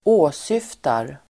Uttal: [²'å:syf:tar]